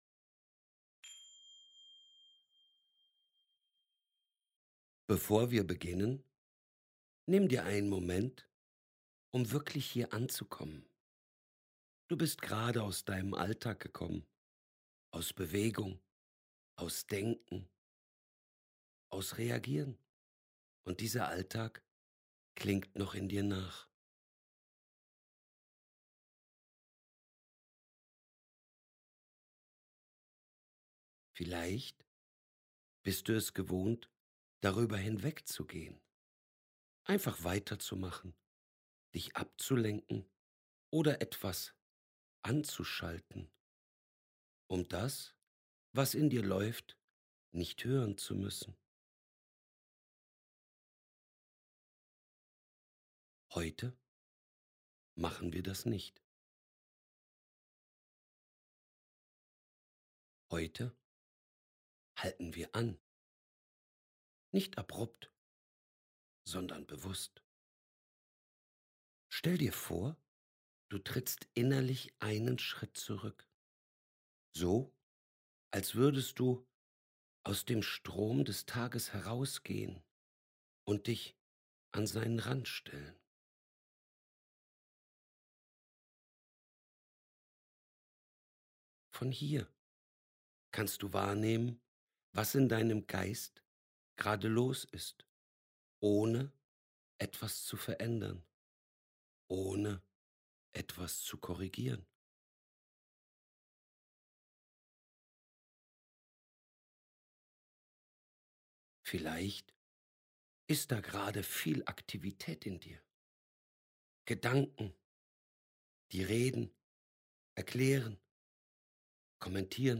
Stille im Geist Geführte Meditation
Die einzelnen Tracks sind klar gesprochen, ruhig geführt und bewusst frei von Musik oder Effekten.
Hochwertige Audioqualität, klare Sprache, kein Hintergrundsound.